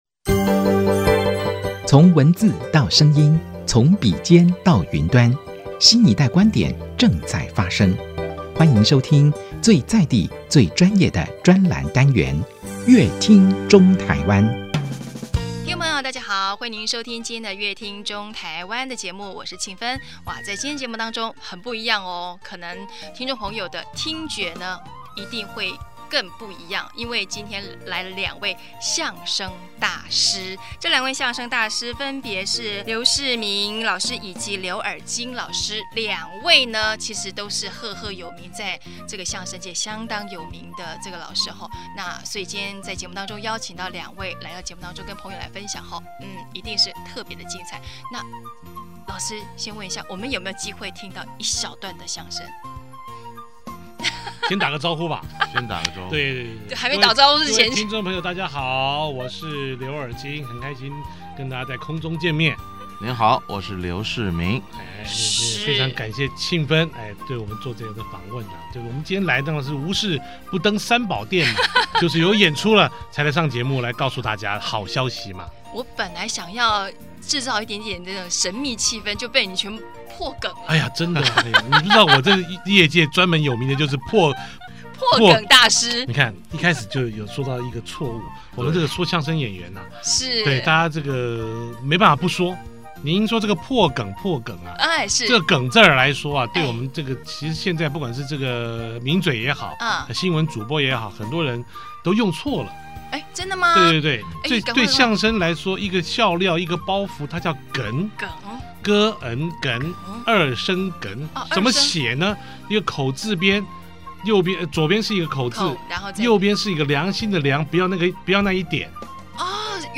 當然節目中現場來一段精彩的對口相聲是一定要的，而二位大師也不負眾望，當場即興演出一段精彩相聲